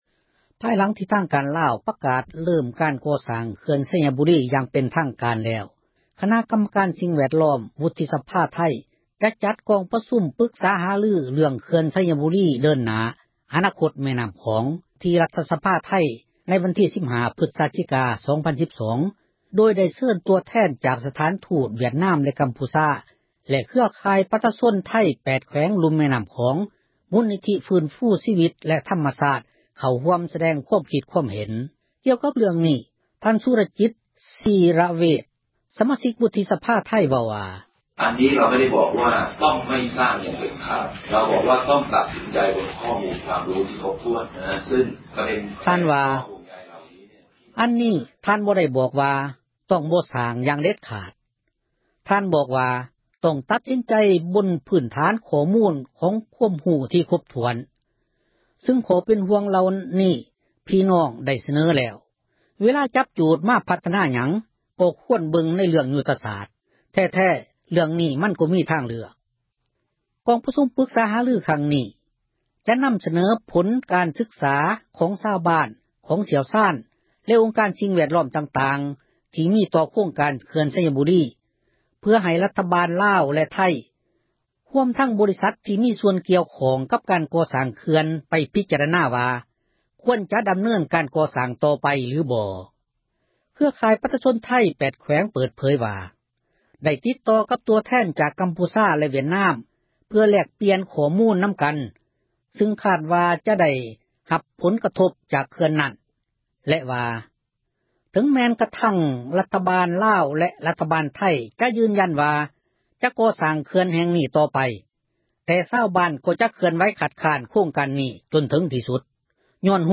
ໂດຍໄດ້ເຊີນ ຕົວແທນ ຈາກ ສະຖານທູຕ ວຽດນາມ ແລະ ກໍາພູຊາ ແລະ ເຄືອຂ່າຍ ປະຊາຊົນໄທ 8 ແຂວງ ລຸ່ມ ແມ່ນໍ້າຂອງ ມູນນິທິ ຟື້ນຟູຊີວິດ ແລະ ທັມມະຊາດ ເຂົ້າຮ່ວມ ສະແດງຄວາມຄິດ ຄວາມເຫັນ. ກ່ຽວກັບເຣື່ອງນີ້ ທ່ານ ສຸຣະຈິດ ຊິຣະເວດ ສະມາຊິກ ວຸທິສະພາໄທ ເວົ້າວ່າ: